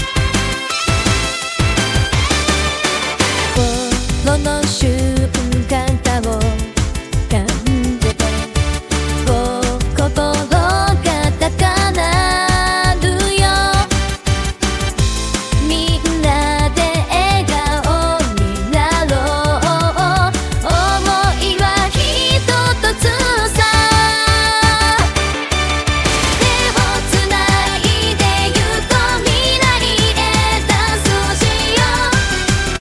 爽やかなJ-POP風
明るくポップな雰囲気のJ-POPをイメージし、使用したい楽器や構成をプロンプトで細かく指定しました。
Album artwork for the bright and uplifting j-pop with female vocals (japanese lyrics). acoustic guitar intro leading to an energetic chorus with driving drums and a melodic bassline. light synth pads in the background. moderate tempo.
※日本語訳：明るく、気分を高揚させるようなJ-POP。女性ボーカル（日本語の歌詞）。アコースティックギターのイントロから始まり、勢いのあるドラムとメロディアスなベースラインが牽引するエネルギッシュなサビへ。背景には軽いシンセパッド。中くらいのテンポ。
生成された曲は、懐かしいJ-POPの雰囲気を持っています。
アコースティックギターの指定は反映されなかったものの、メロディや構成はプロンプト通りでした。